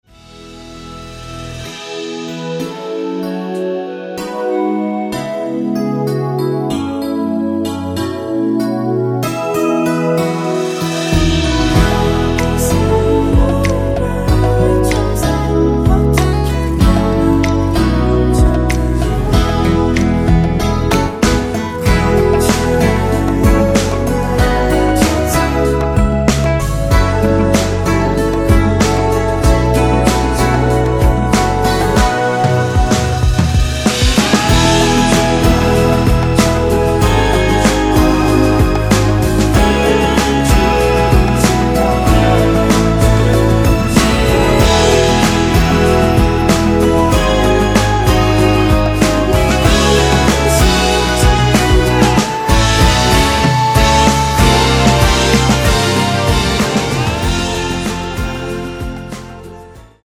원키 멜로디와 코러스 포함된 MR입니다.(미리듣기 참고)
앞부분30초, 뒷부분30초씩 편집해서 올려 드리고 있습니다.